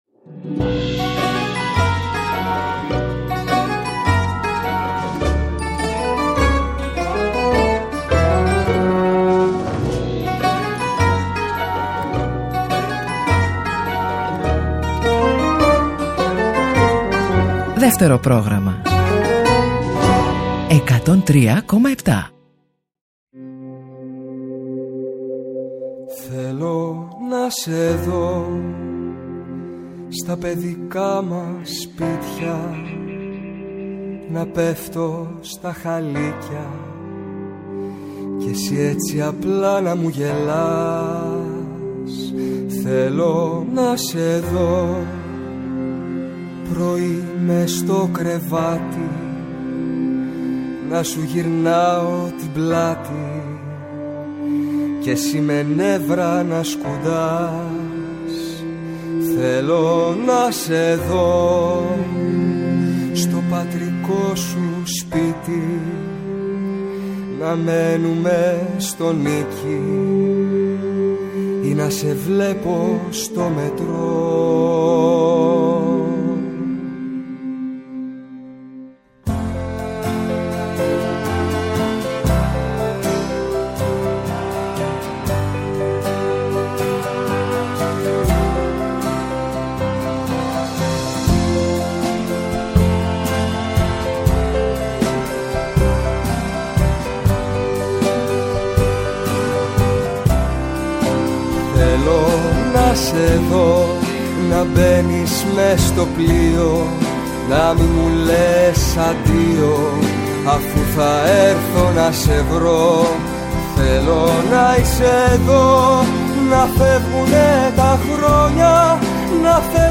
Ακολουθεί μια κουβέντα